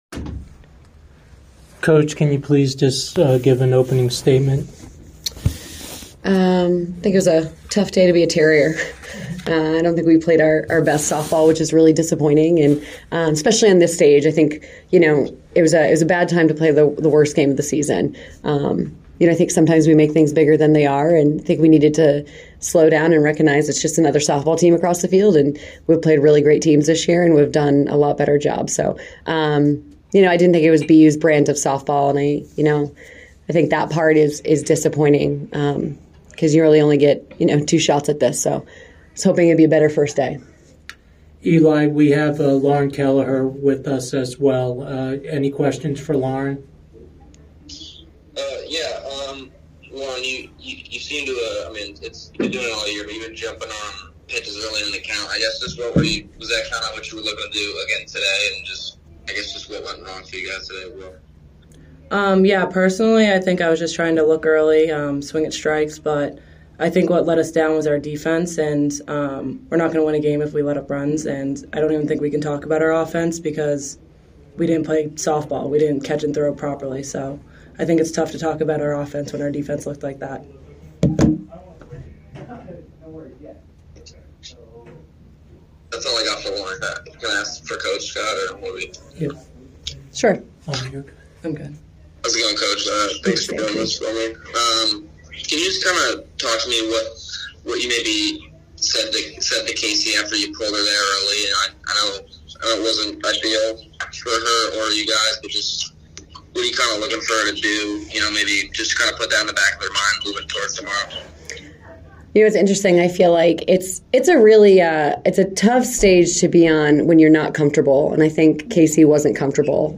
Oregon Postgame Interview